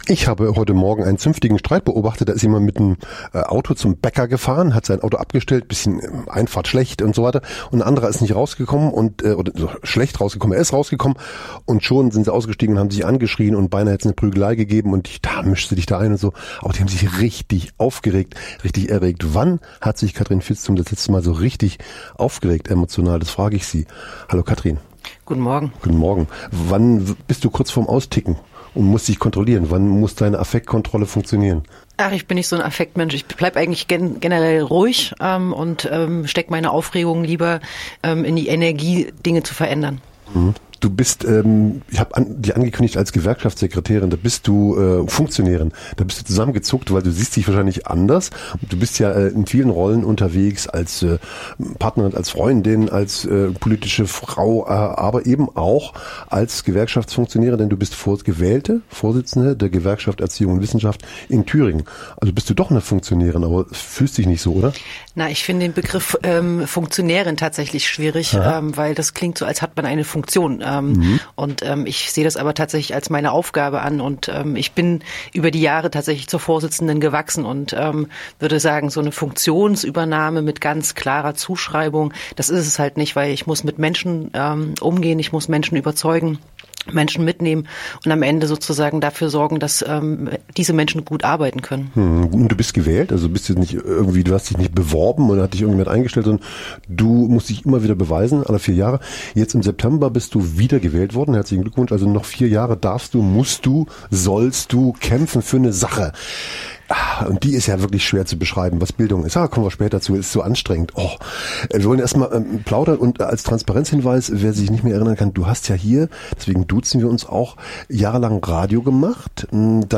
Unpolitisch gibt es nicht. Nichts gibt es ohne Kampf | Montagsgespräch